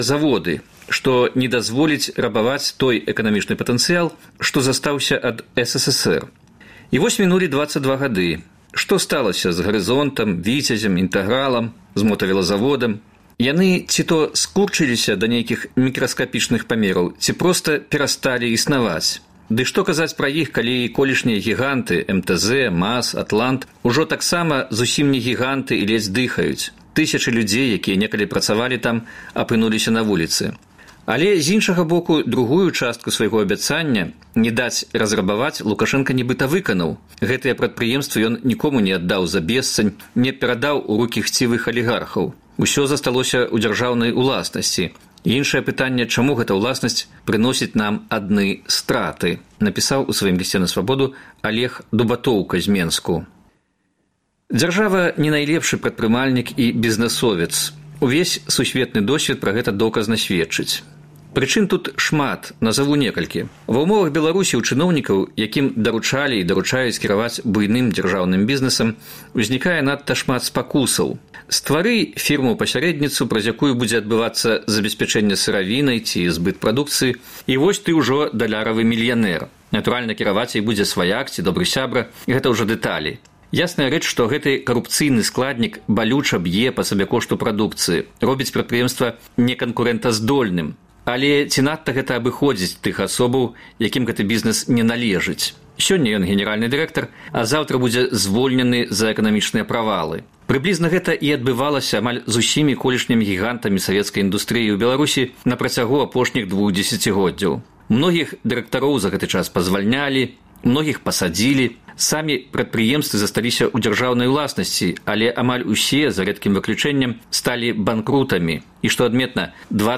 Лісты чытачоў на Свабоду чытае і камэнтуе